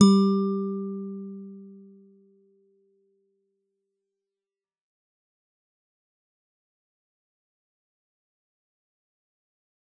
G_Musicbox-G3-f.wav